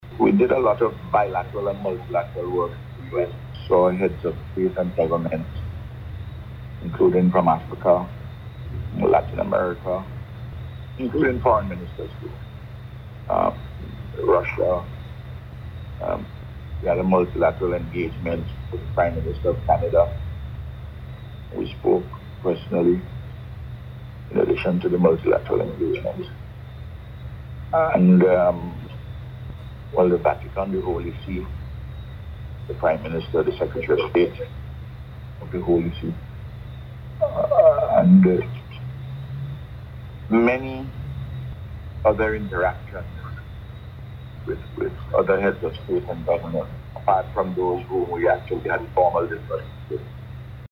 Prime Minister Gonsalves, who is in London provided an update on his overseas engagements on Radio yesterday.